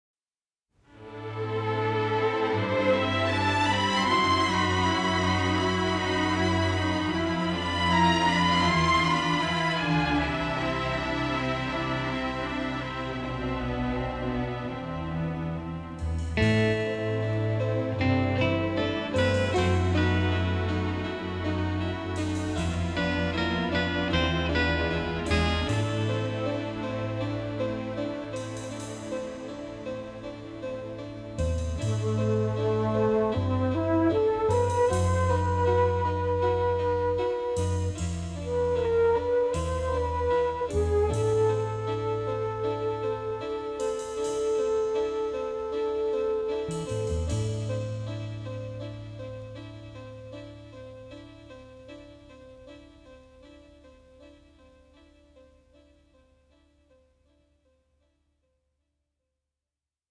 (strumentale #9) 23